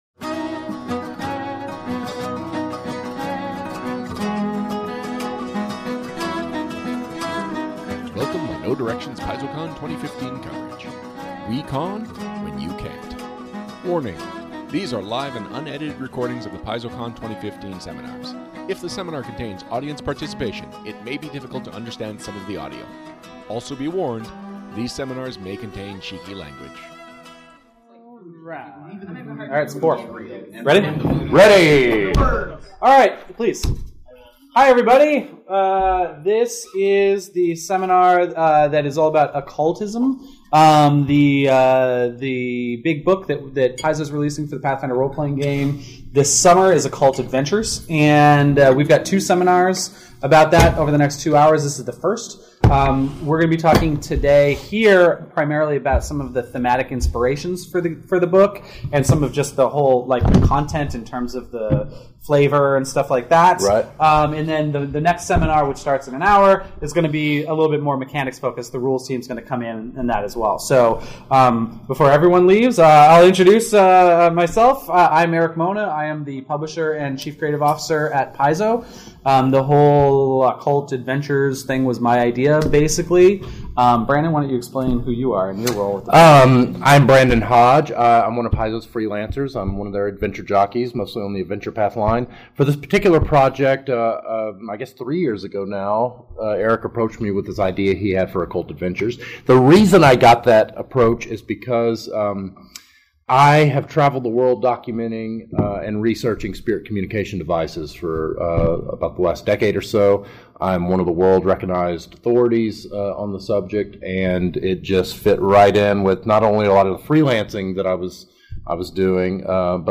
Warning – These are live and unedited recordings of the PaizoCon 2015 seminars. If the seminar contains audience participation, it may be difficult to understand some of the audio. Also be warned – these seminars may contain cheeky language.